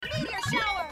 Play, download and share Meteor Shower! original sound button!!!!
meteor-shower.mp3